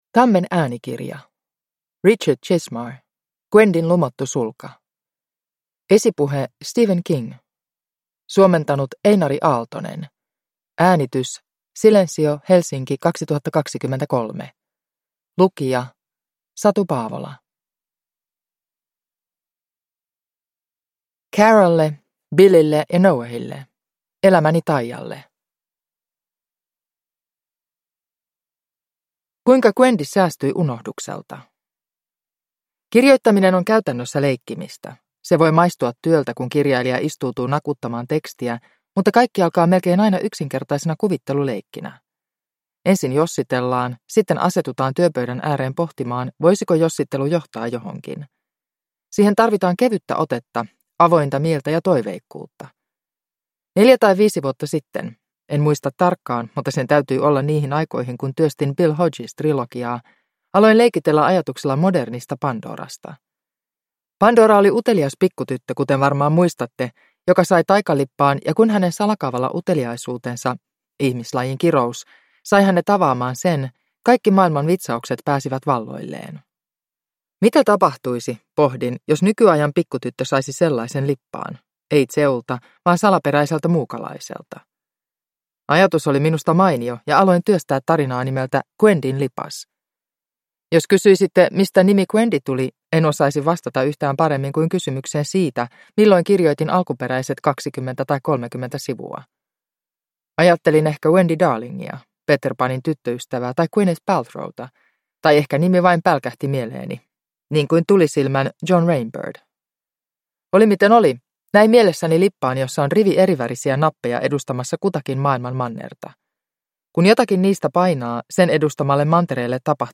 Gwendyn lumottu sulka – Ljudbok – Laddas ner